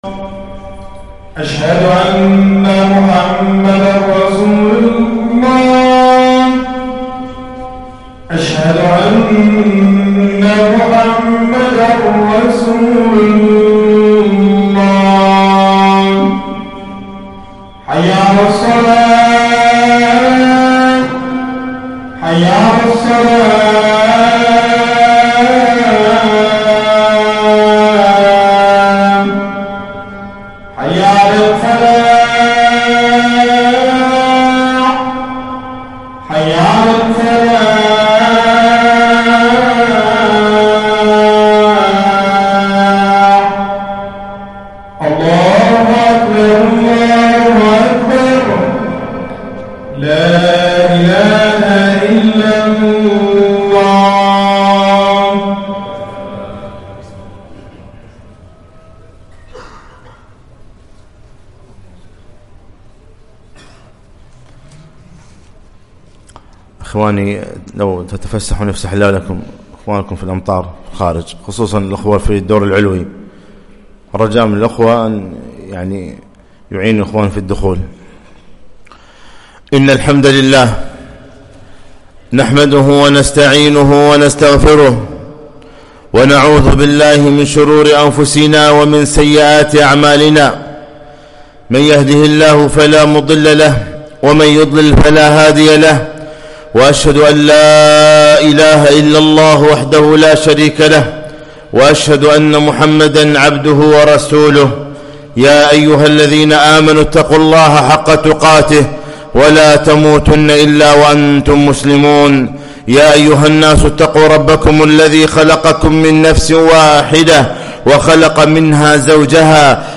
خطبة - ( حقوق الراعي والرعية )